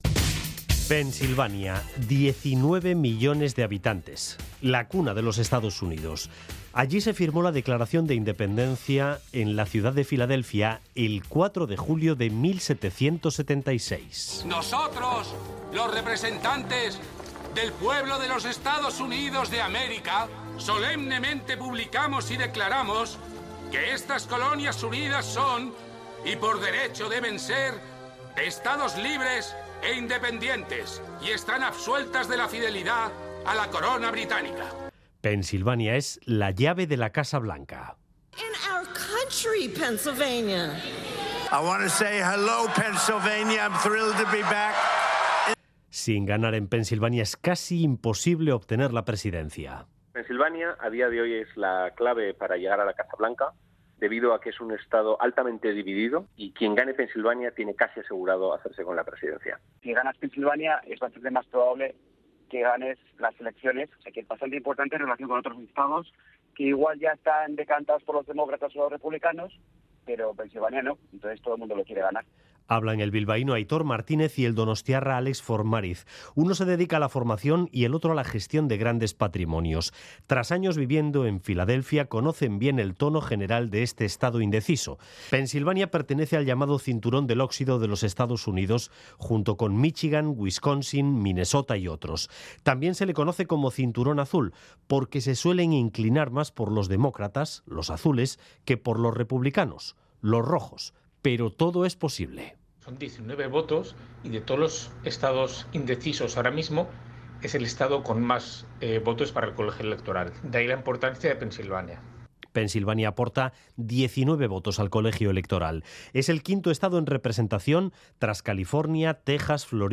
En este reportaje